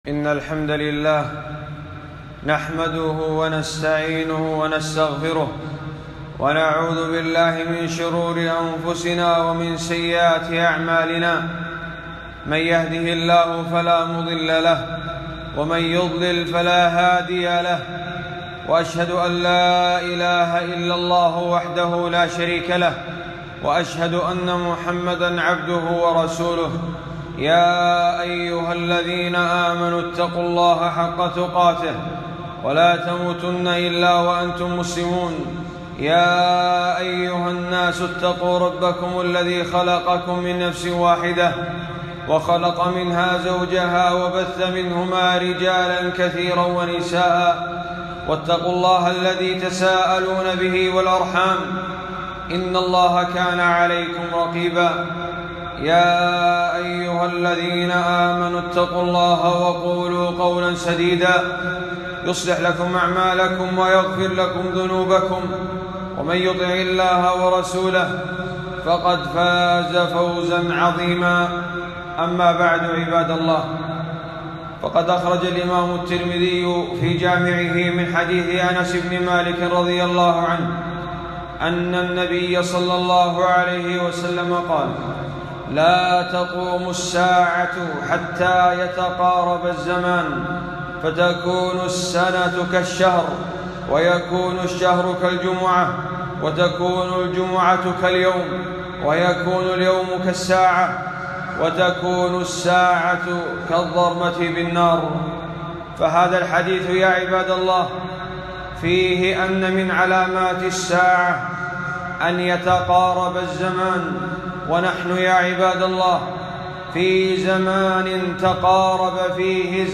خطبة - عشر ذي الحجة وأهم أعمالها